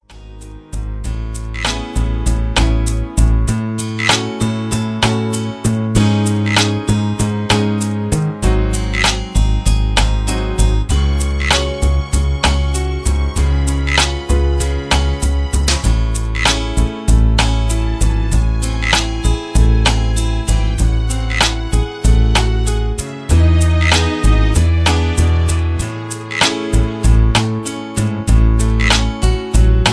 Karaoke MP3 Backing Tracks
Just Plain & Simply "GREAT MUSIC" (No Lyrics).
Tags: karaoke , mp3 backing tracks